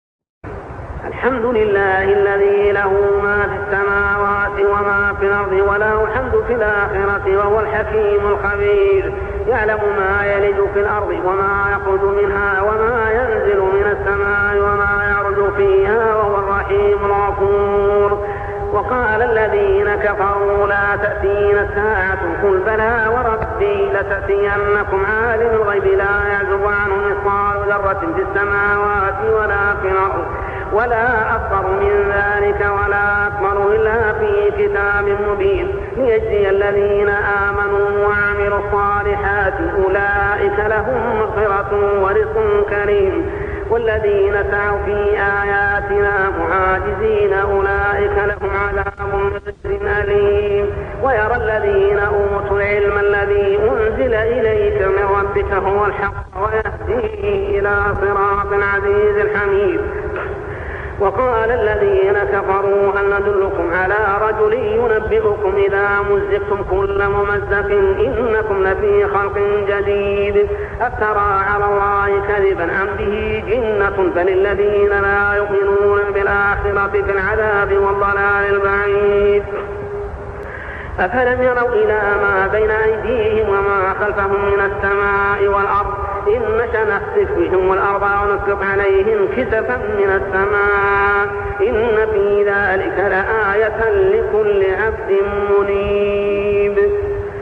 صلاة التراويح عام 1401هـ سورة سبإ 1-9 | Tarawih prayer Surah Saba > تراويح الحرم المكي عام 1401 🕋 > التراويح - تلاوات الحرمين